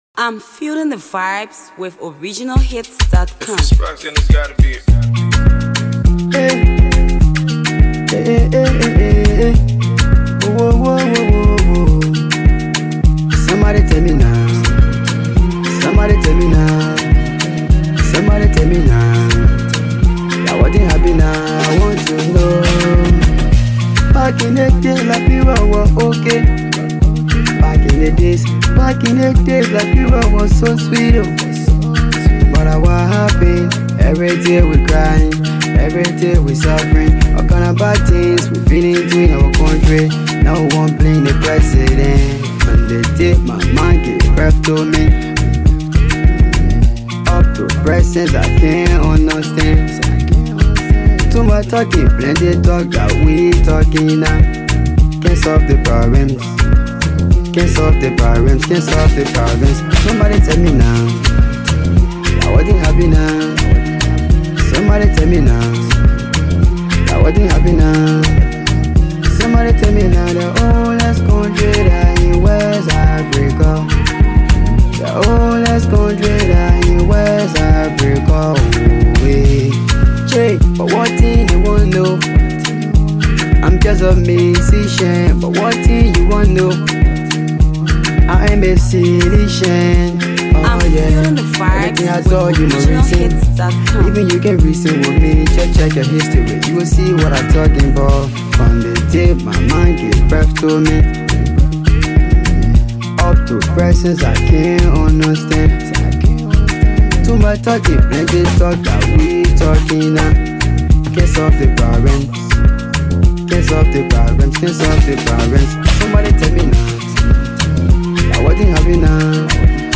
Afro Music